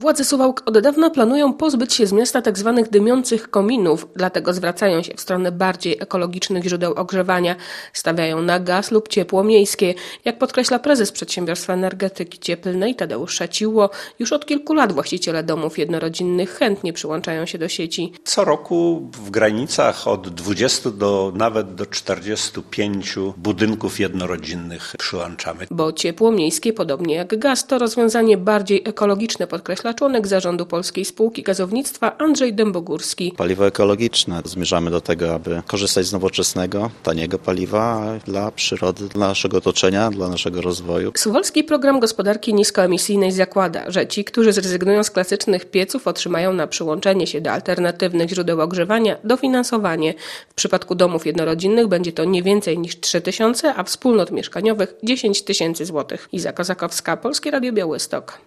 W Suwałkach rozpoczyna działalność Ośrodek Profilaktyki Pomocy Osobom Nietrzeźwym i Bezdomnym - relacja